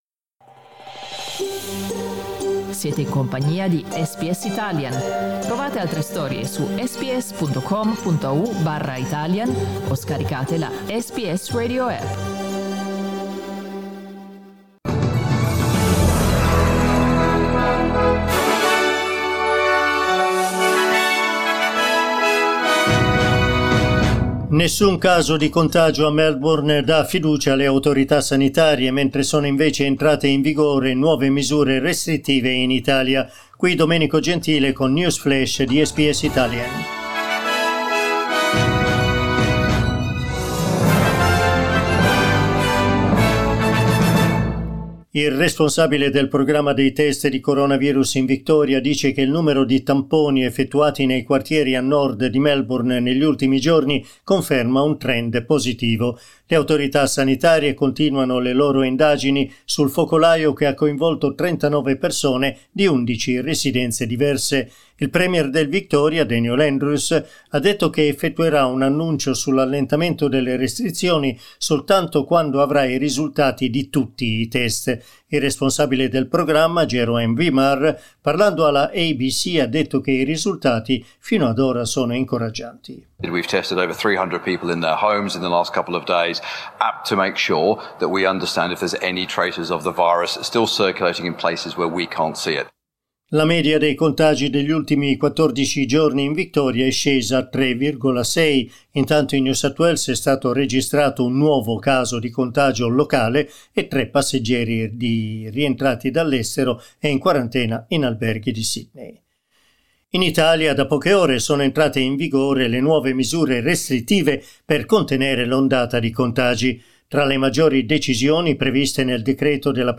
News Flash lunedì 26 ottobre
Aggiornamento delle notizie di SBS Italian.